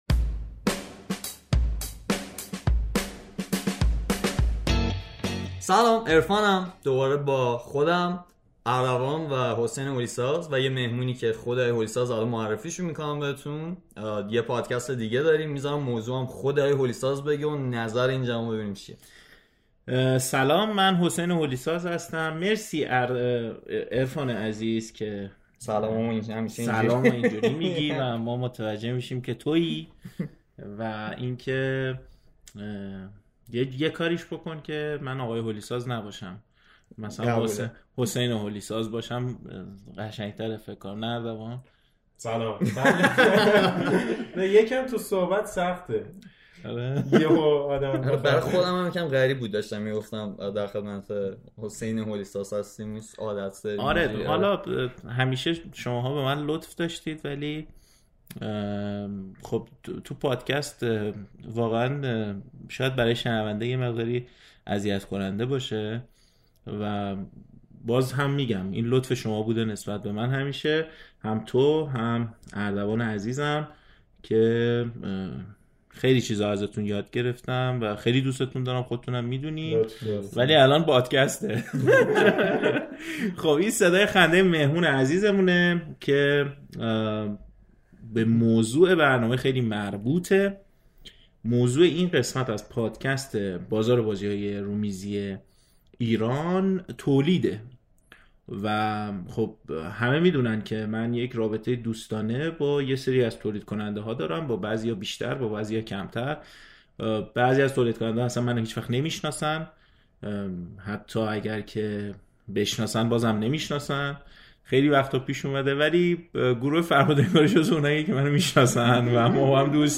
اینکه صداها تنظیم نیست..